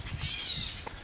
crying bird crying bird:
crying bird.AIF